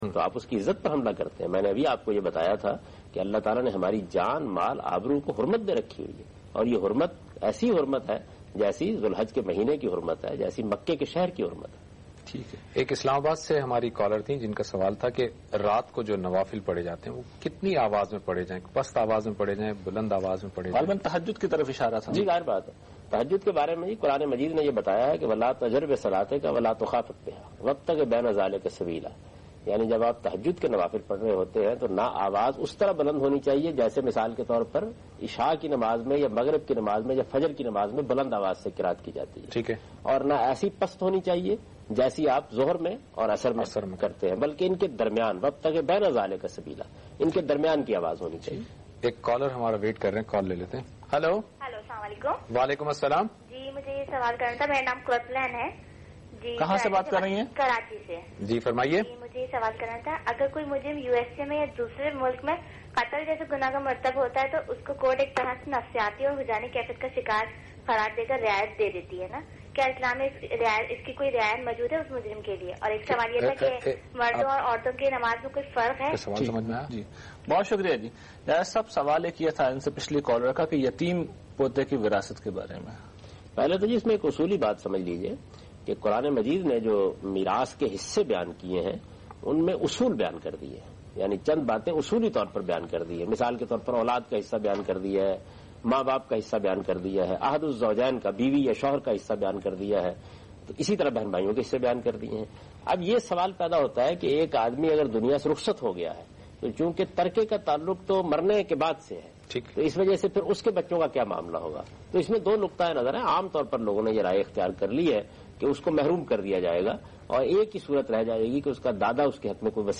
The program was aired on Aaj TV (in Pakistan) in the year 2006.